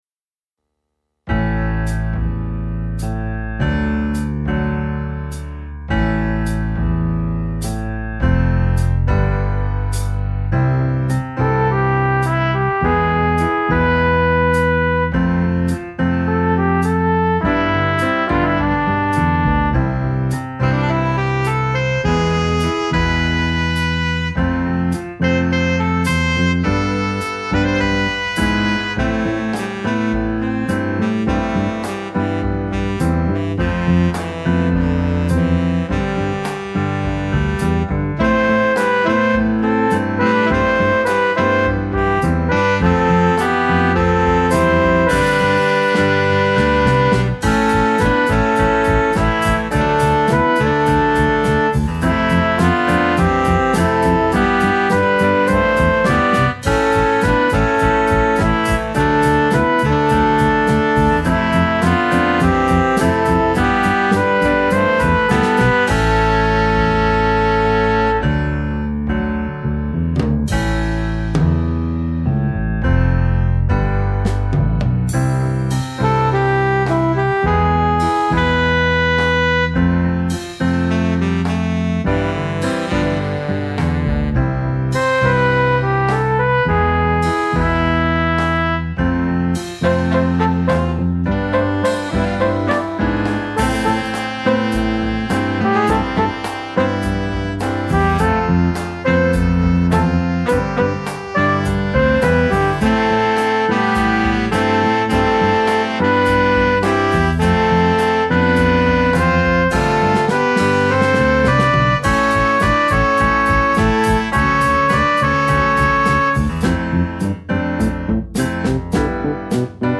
Instrumentation: C, Bb, Eb, Bass, Drums, Gtr Chords
A light jazz piece providing a good introduction